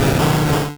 Cri de Saquedeneu dans Pokémon Rouge et Bleu.